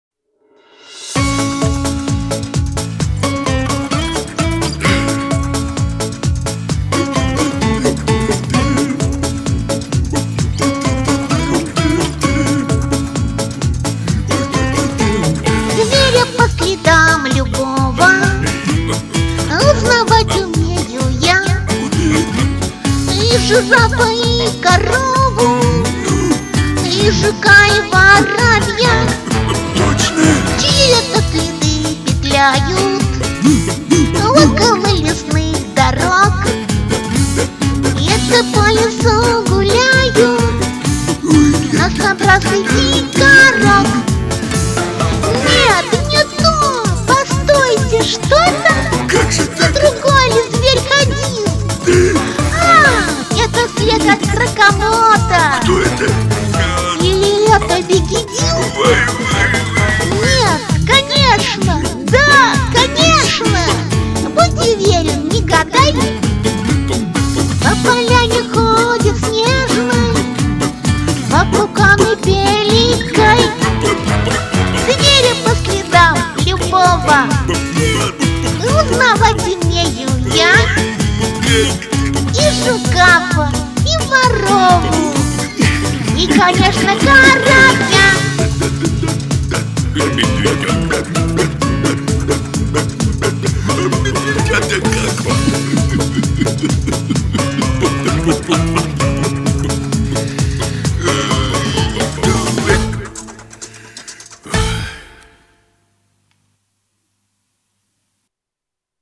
оживили песенку бэки, прикольно очень звучит))))
А запись... запись была сделана онлайн на соседнем сайте...
так изобразить нечеткую речь ребенка тоже еще уметь надо!